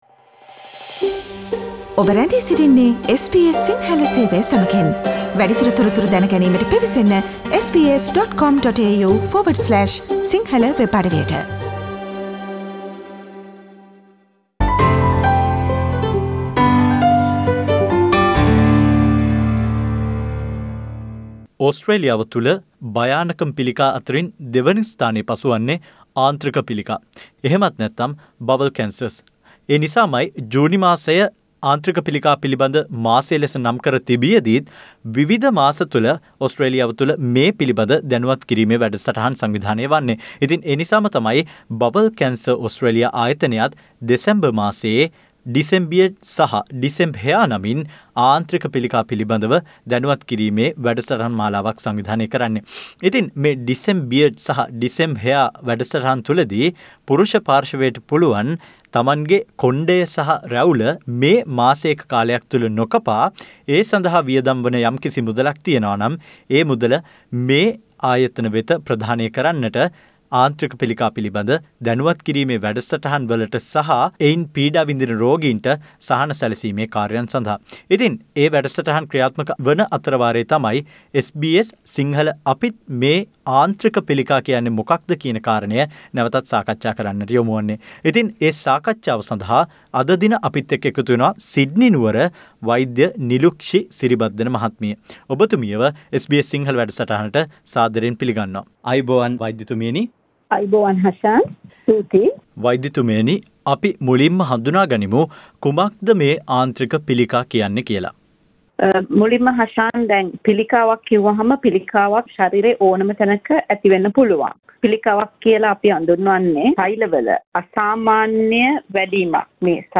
Click on the speaker icon on above image to listen to SBS Sinhala Radio's discussion about Bowel Cancer causes and precautions. This coincides with Decembeard/Decembhair organised by Bowel Cancer Australia.